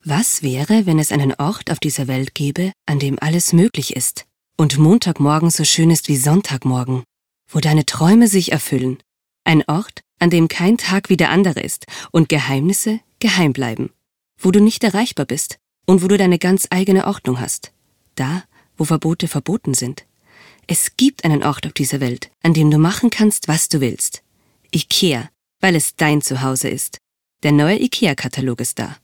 Sie suchen eine Sprecherin für verschiedenste Aufnahmen?
Wersbespot “IKEA”
Werbespot-IKEA.mp3